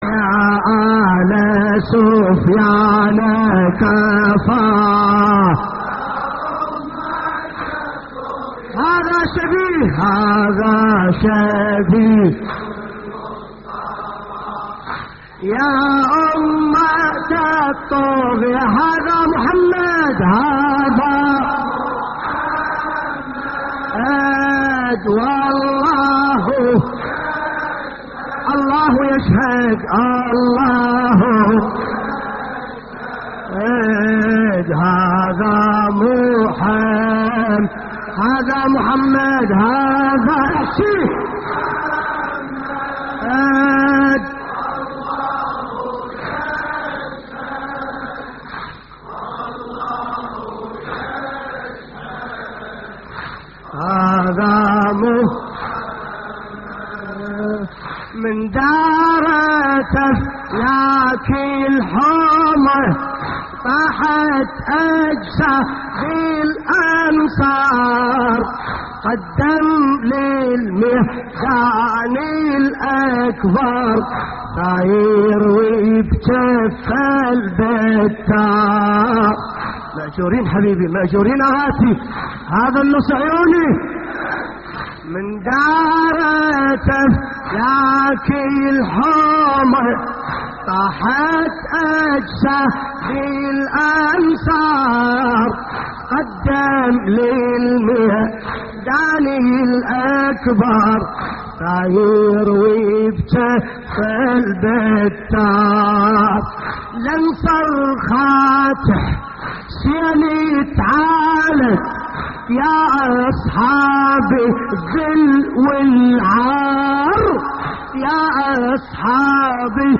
تحميل : يا آل سفيان كفى يا أمة الطغيان هذا شبيه المصطفى / الرادود باسم الكربلائي / اللطميات الحسينية / موقع يا حسين